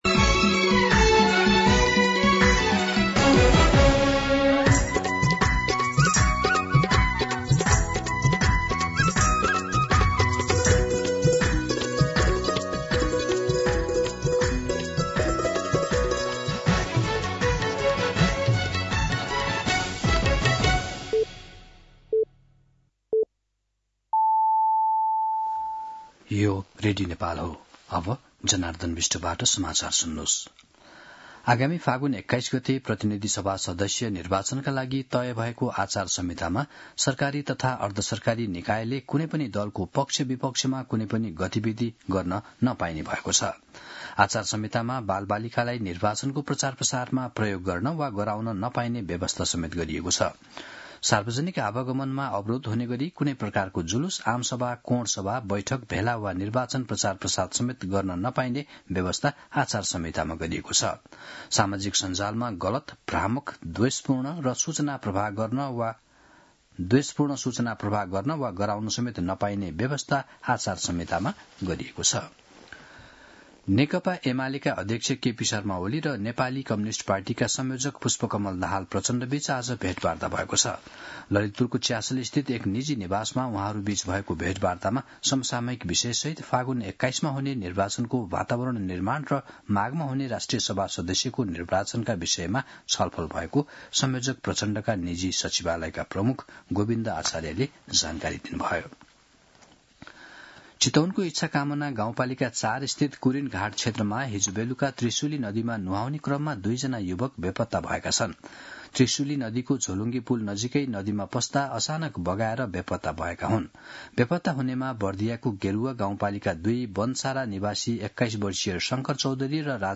दिउँसो १ बजेको नेपाली समाचार : २० पुष , २०८२
1-pm-Nepali-News-2.mp3